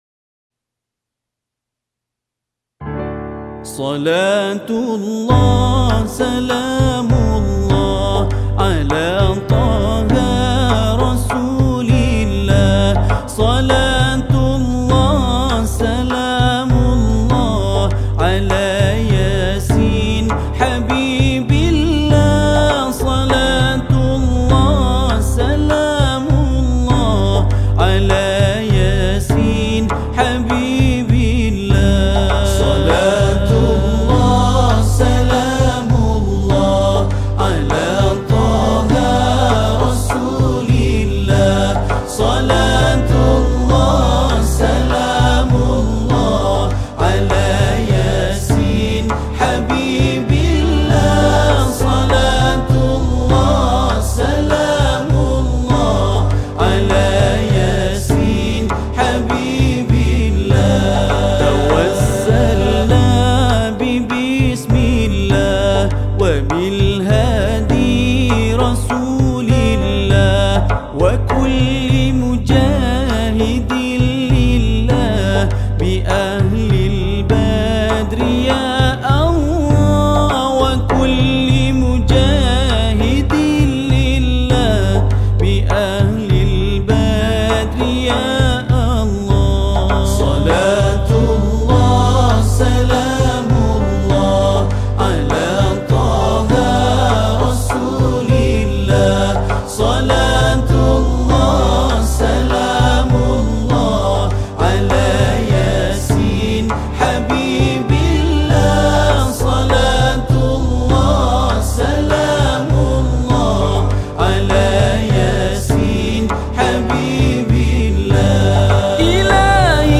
Arabic Songs
Nasyid Songs
Solo Recorder